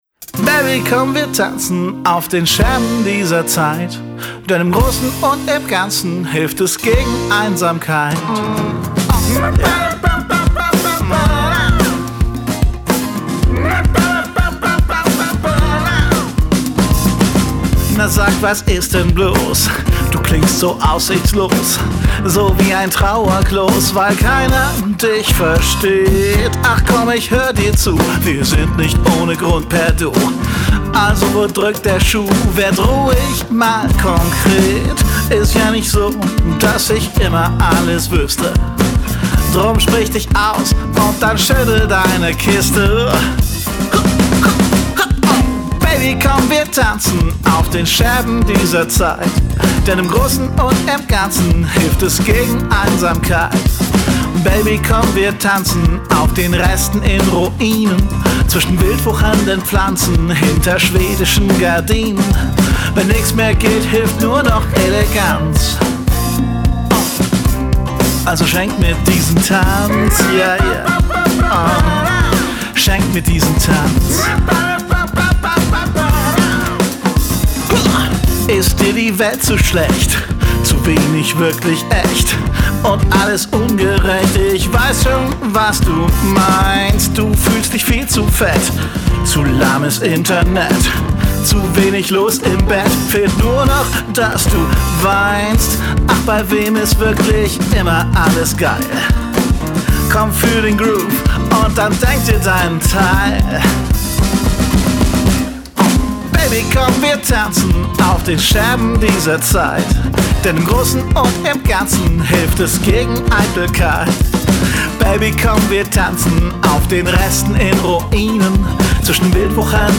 Gitarren, Gesänge
Drums, Bass.
Congas.